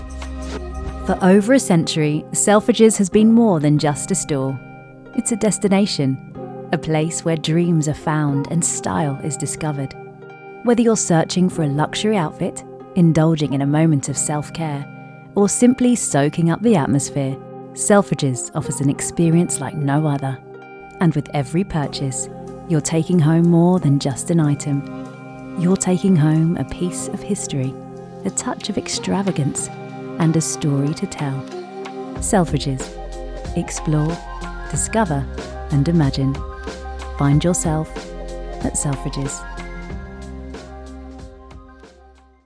RP Smooth Sophisticated
RP ('Received Pronunciation')